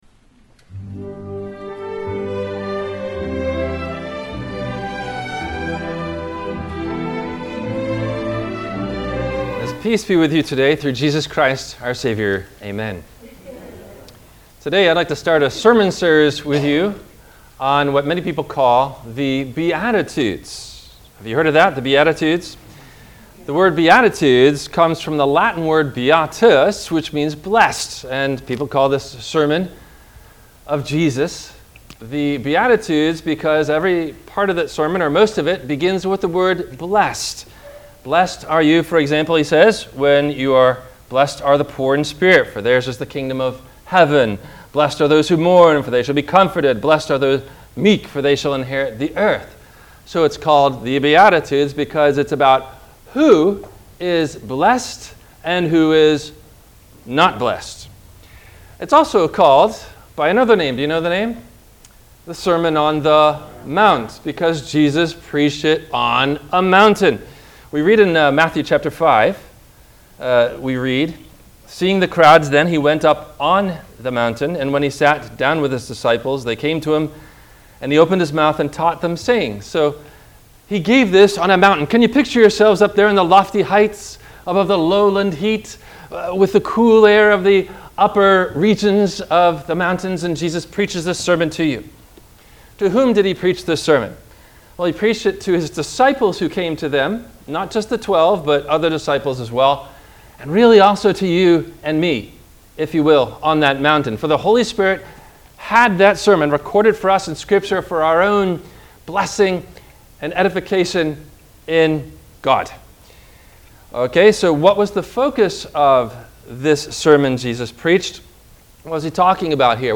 What’s It Mean … Blessed Are The Poor In Spirit? – WMIE Radio Sermon – August 14 2023